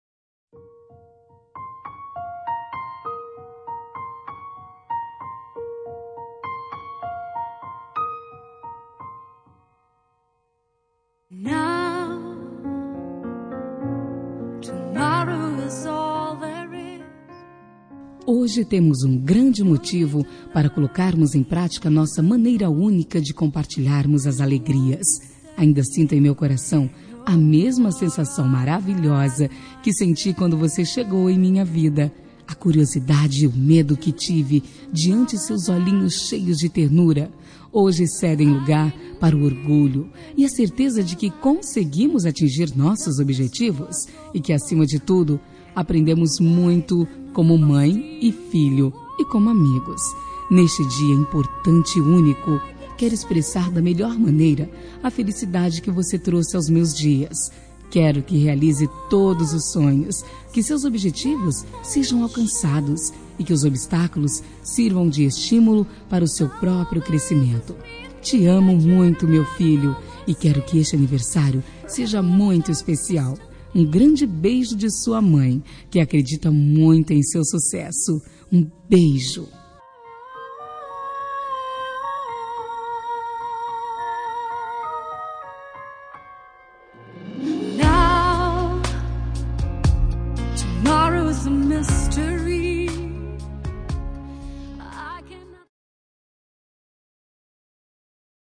Telemensagem de Aniversário de Filho – Voz Feminina – Cód: 1831